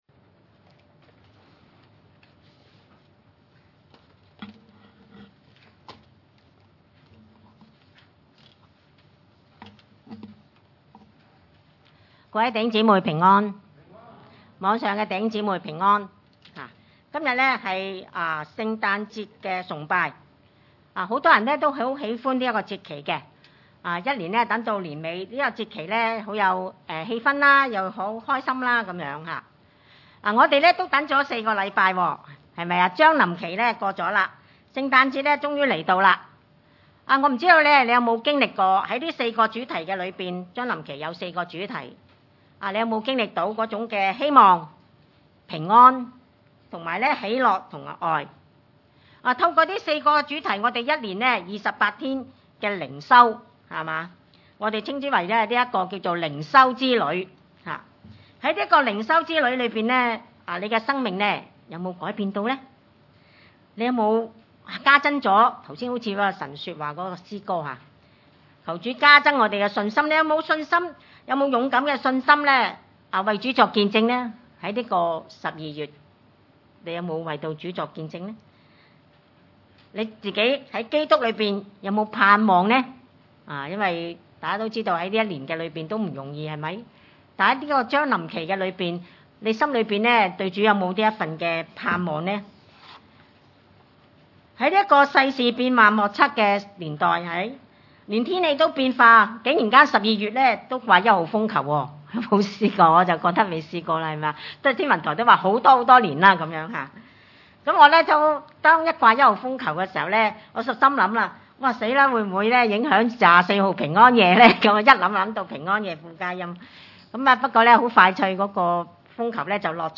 路加福音2：8-38 崇拜類別: 主日午堂崇拜 8在伯利恆的野外有牧羊人，夜間值班看守羊群。